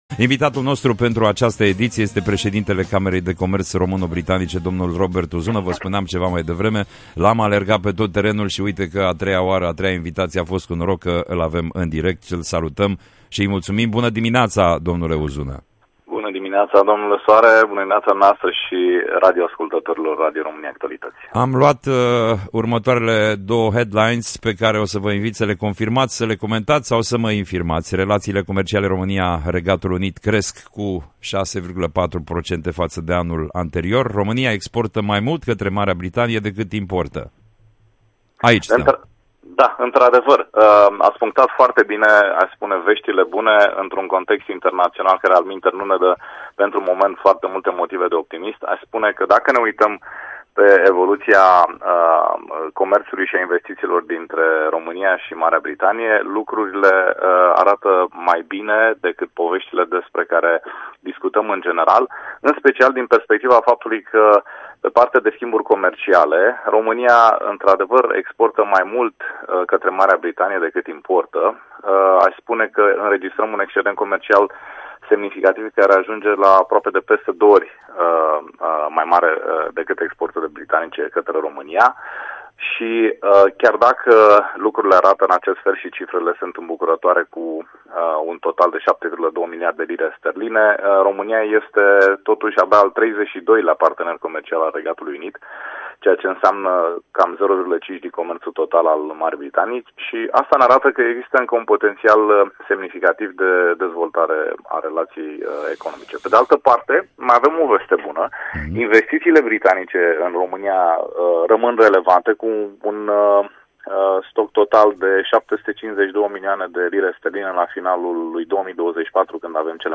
Strengthening Romania–UK trade: An interview